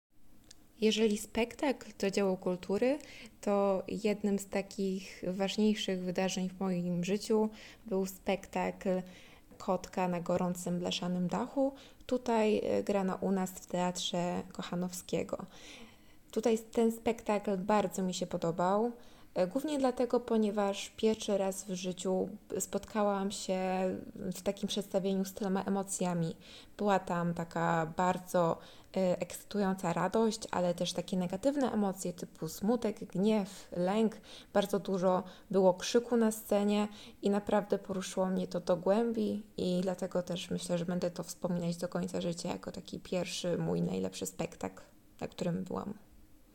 Zapytaliśmy studentów, o wspomnienia dzieł, które towarzyszą im przez całe życie i dlaczego są dla nich tak ważne: